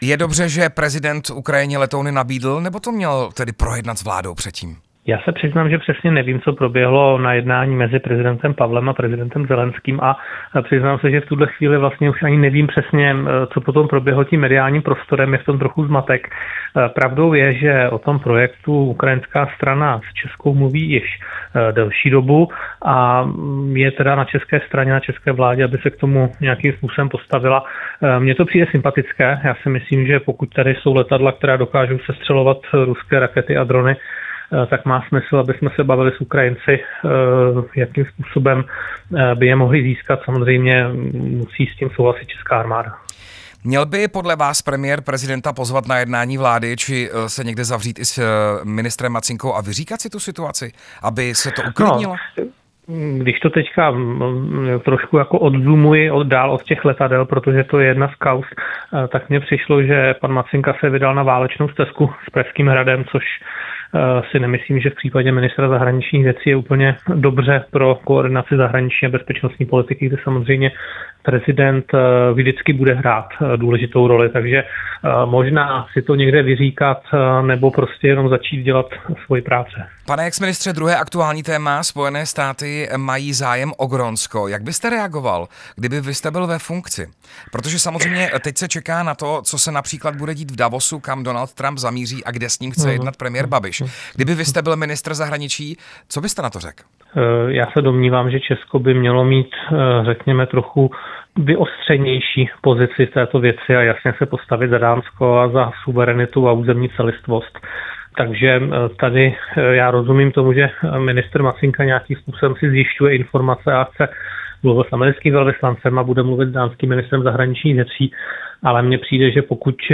Bývalý ministr zahraničních věcí Jan Lipavský v aktuálním rozhovoru